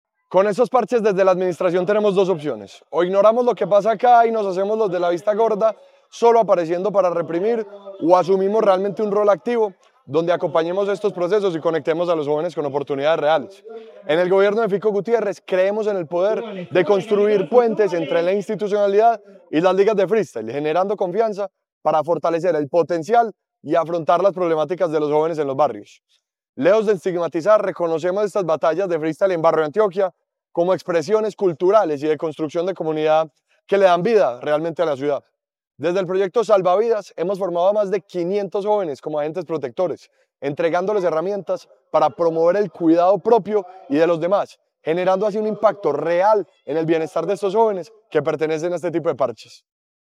Declaraciones-del-secretario-de-Juventud-Ricardo-Jaramillo-Velez.mp3